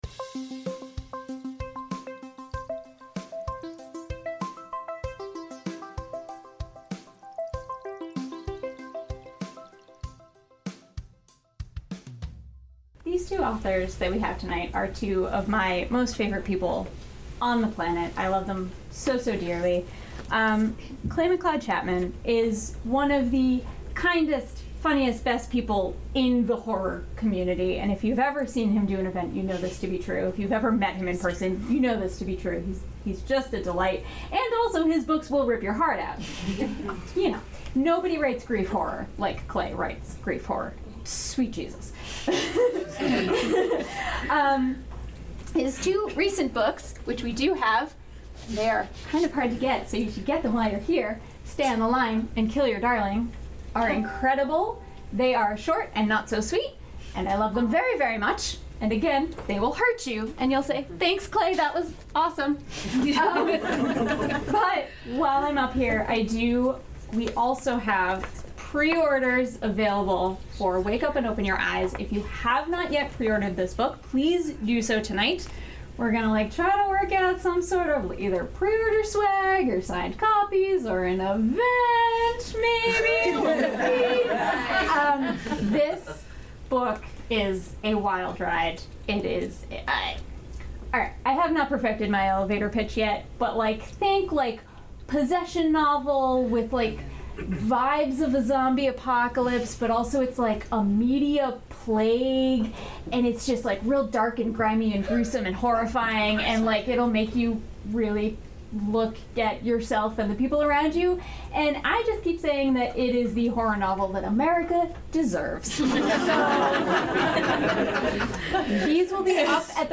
We set up a microphone between them and recorded that audio for you.